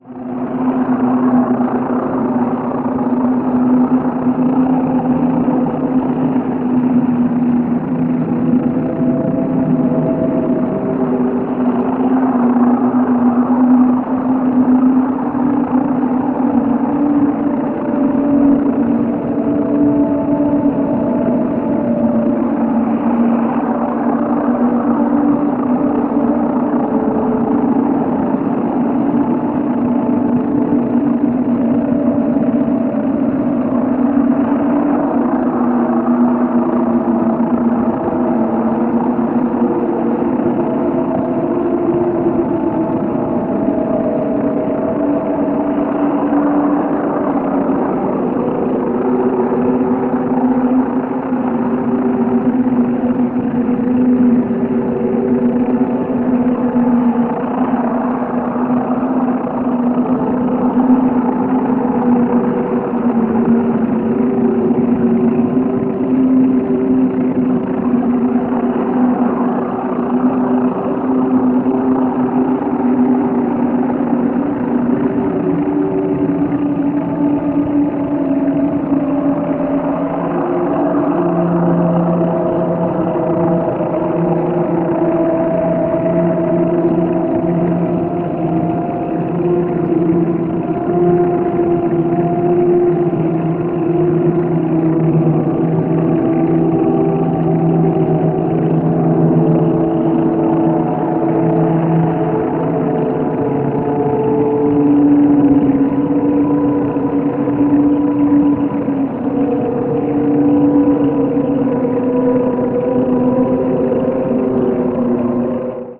25分超えの長編腐食ドローン2作品を収録した終始ズブズブなテクスチャーが続く一枚。